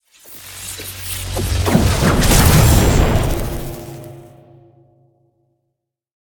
teleport2.ogg